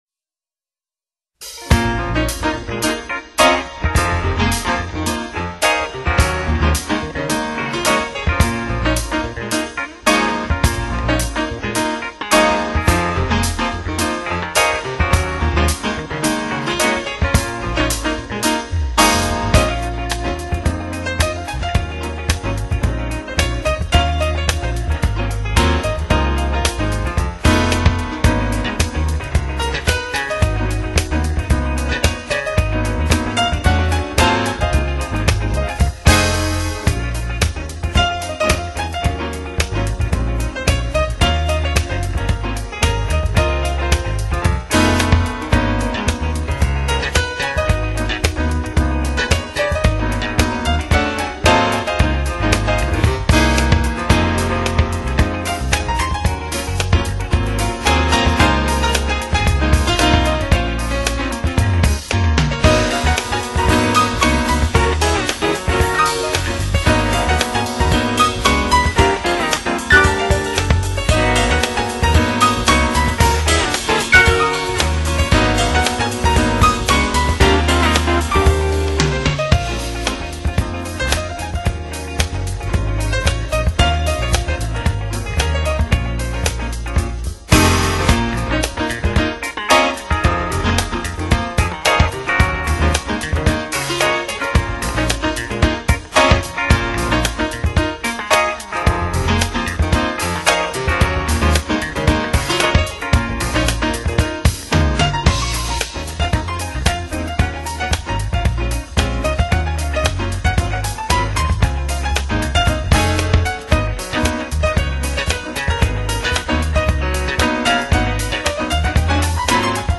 Blues And Jazz